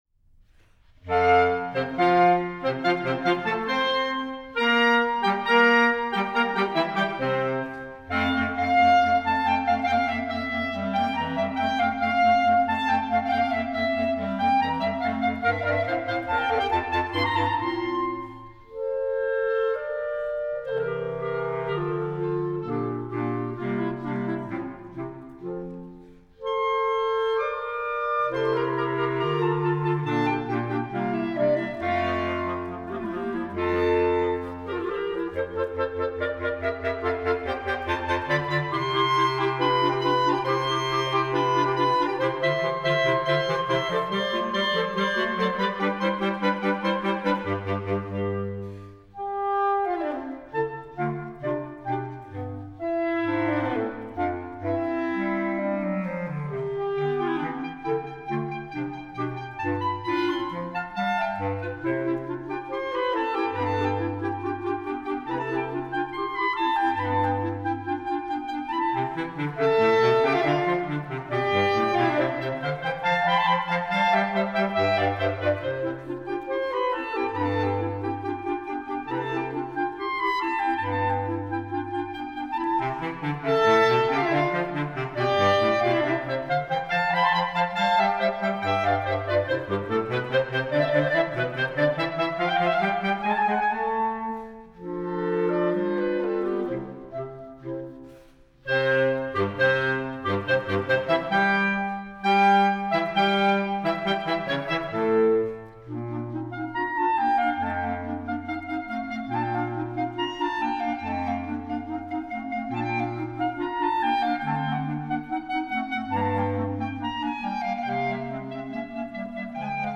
Noten für flexibles Ensemble.